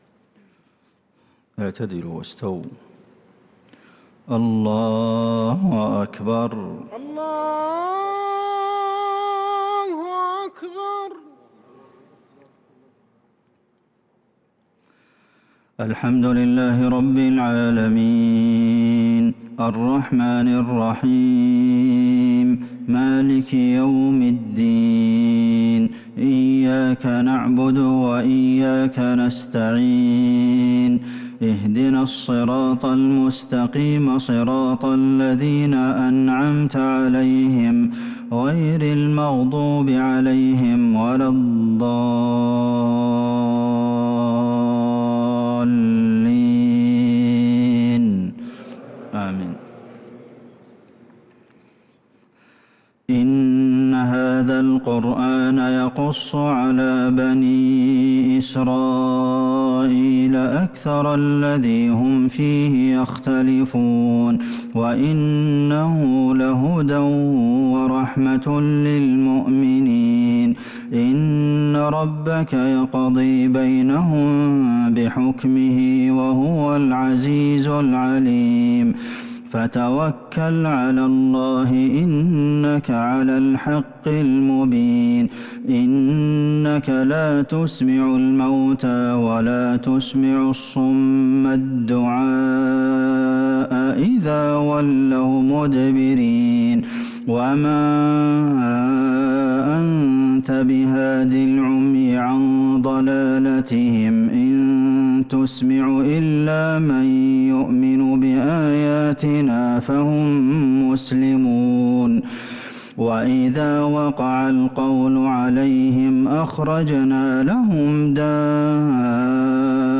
عشاء الثلاثاء2 شوال 1443هـ خواتيم سورة النمل | Isha prayer from Surah an-Naml 3-5-2022 > 1443 🕌 > الفروض - تلاوات الحرمين